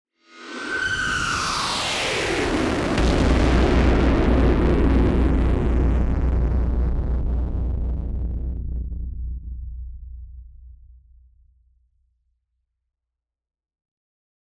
BF_DrumBombDrop-02.wav